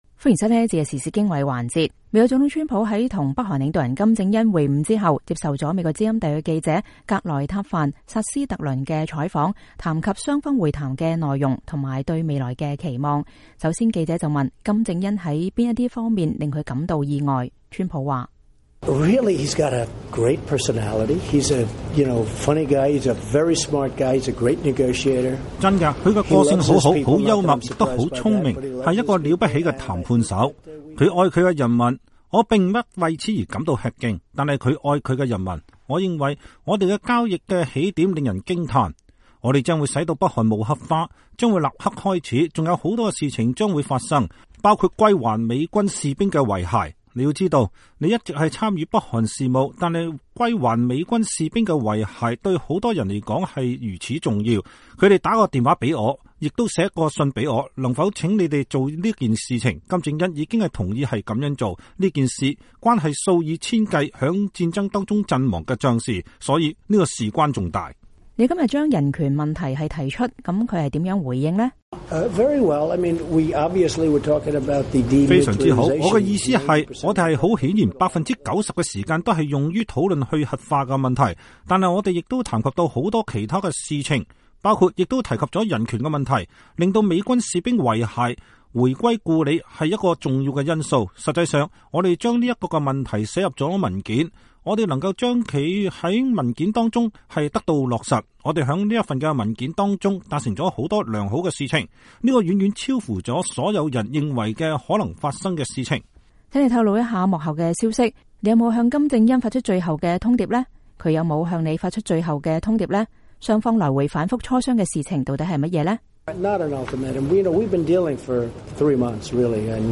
美國北韓峰會：川普總統接受美國之音專訪